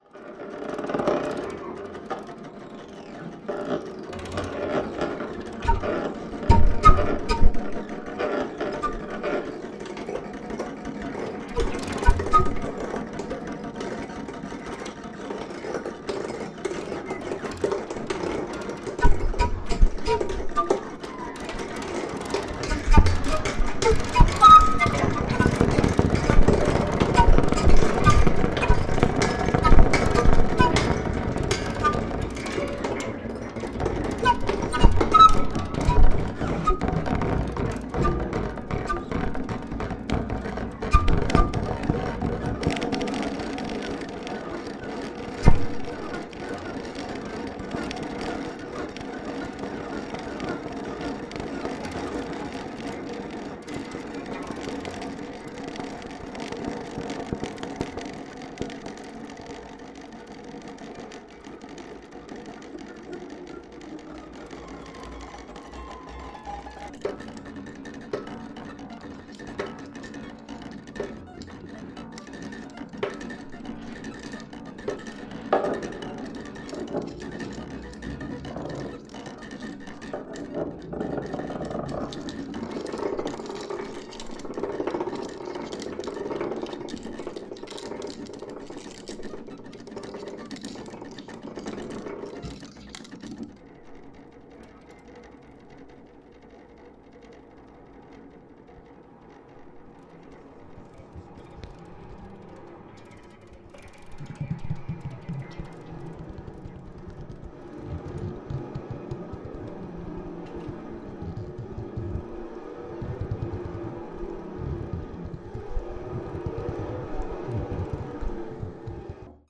演奏者それぞれがクラヴィコードを使った凄まじくシリアスな鉱物即興&ライブエレクトロニクス作
キリキリと恐ろしい摩擦音と無骨な打撃の集積はもはや物音的な領域。
free improvised music by
monochord, clavichord, melodica
clavichord, electronics
tenor and bass recorder